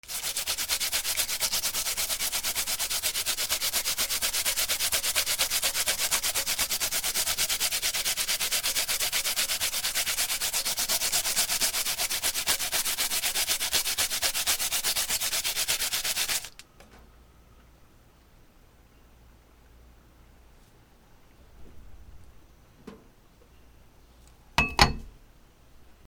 山芋をする 料理
『シュシュ』